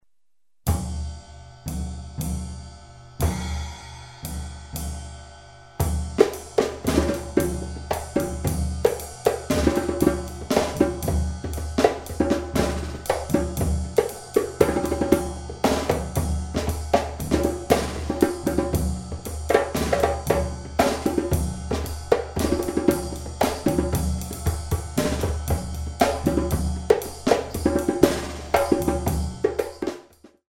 mm=118